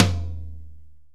Index of /90_sSampleCDs/AMG - Now CD-ROM (Roland)/DRM_NOW! Drums/TOM_NOW! Toms
TOM JJ TOM 1.wav